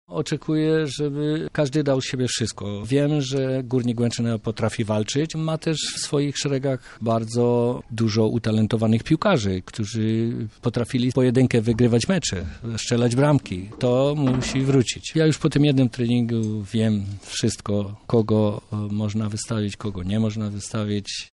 – mówił dzisiaj na spotkaniu z dziennikarzami nowy trener Łęcznian.